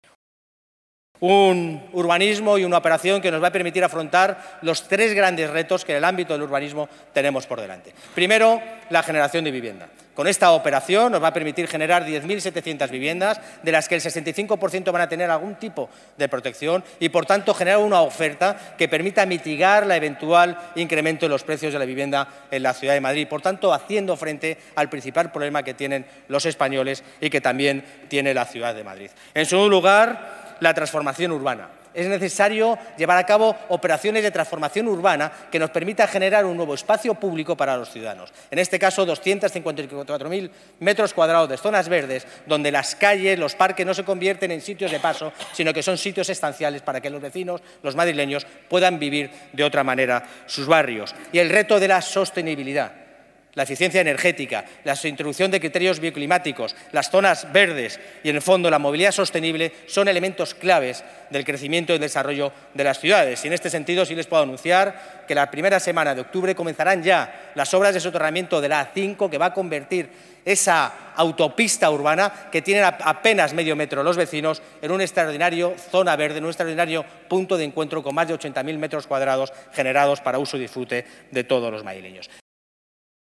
Pleno del Ayuntamiento de Madrid de 24 de septiembre de 2024